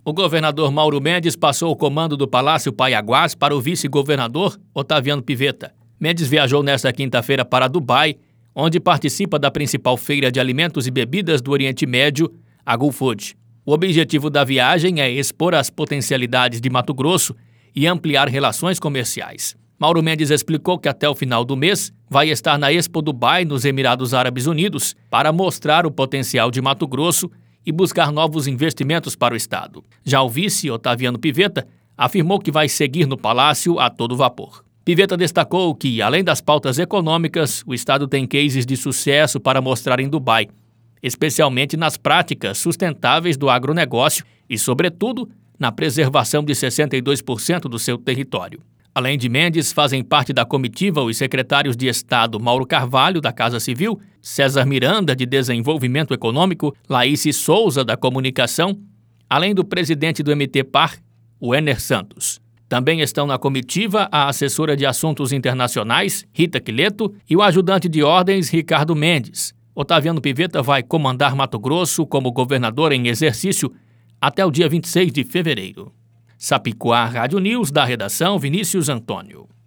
Boletins de MT 18 fev, 2022